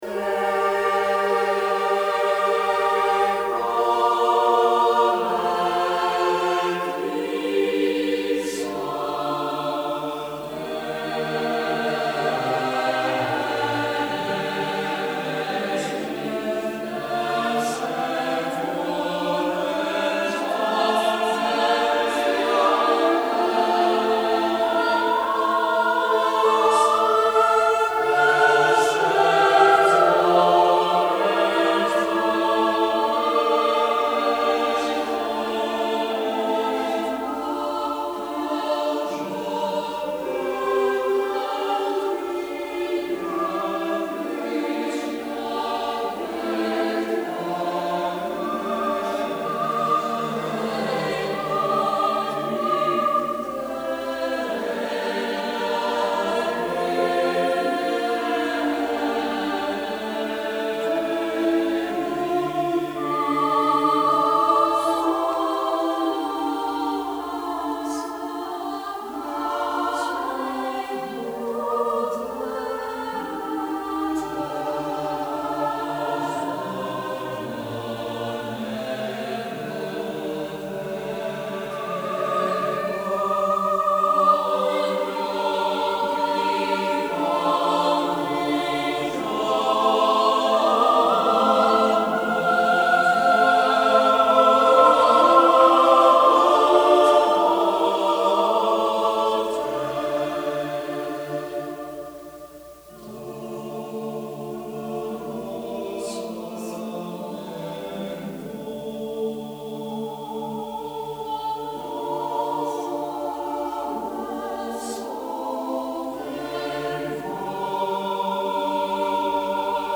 Tempo is slow . . . not entirely inappropriate for the elegiac content . . . and it allows the wonderful dissonant harmonies to sound.